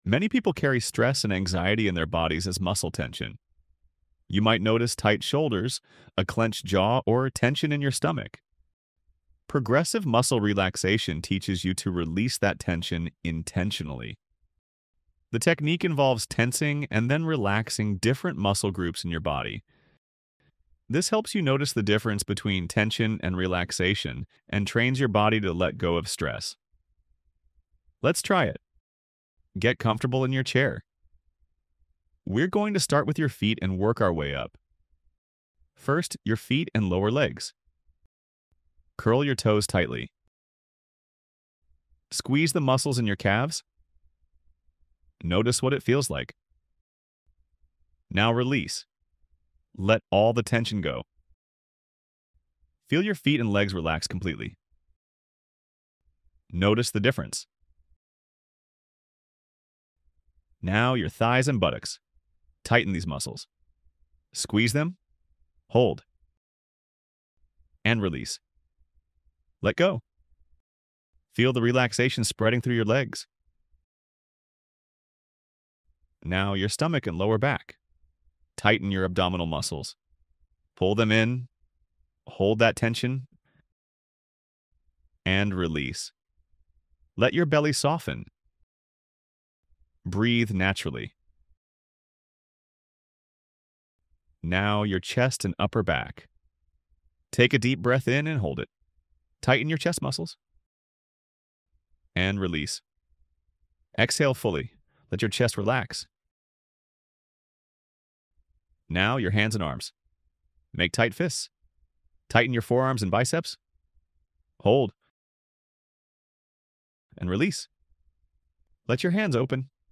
Free audio-guided exercises for EMDR Phase 2 preparation